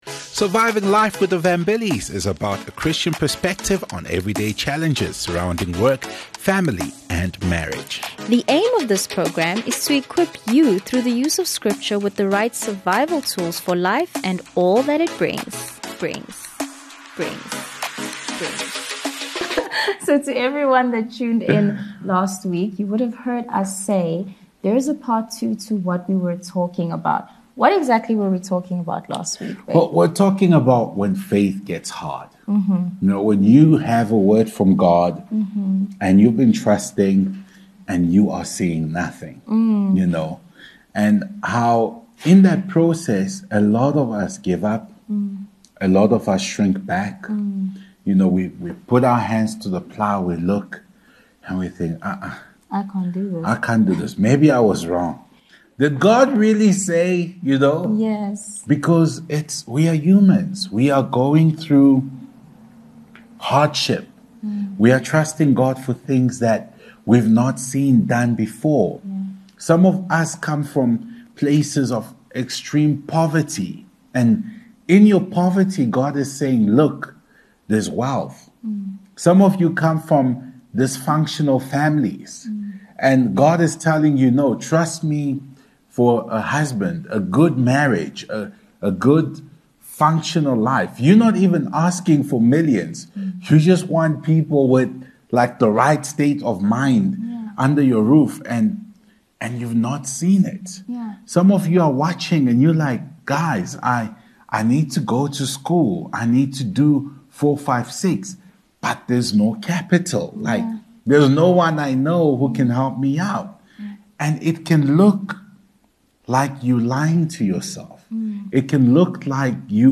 Prerecorded